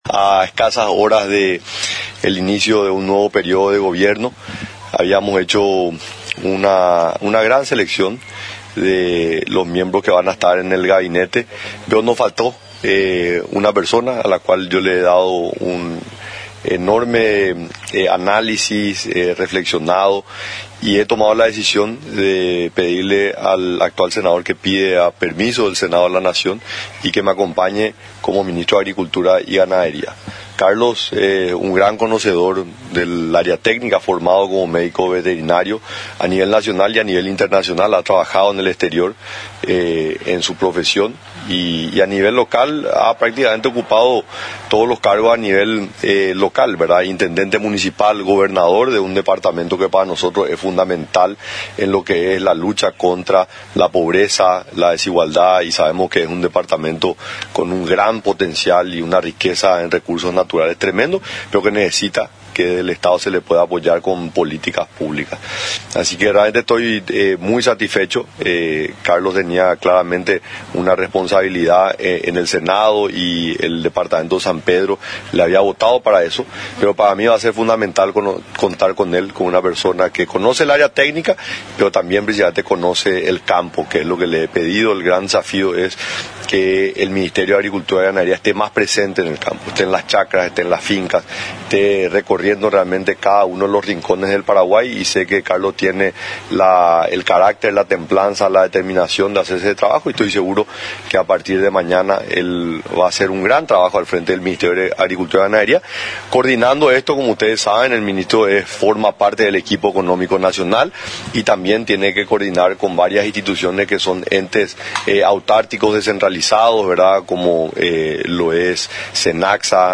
El Dr. Carlos Giménez, es el elegido como futuro ministro de Agricultura y Ganadería. Así adelantó en conferencia de prensa, el presidente electo Santiago Peña, quien anunció a otro miembro de su gabinete para asumir al frente de la cartera agraria a partir de este 15 de agosto.
El anuncio fue dado este lunes frente a su residencia, tal como lo viene haciendo con todos las designaciones recientemente oficializadas, en donde el próximo titular del MAG expresó su compromiso de trabajar para dar respuesta a las familias de la Agricultura Familiar Campesina.
EDITADO-3-SANTIAGO-PENA-PRESIDENTE-ELECTO-1.mp3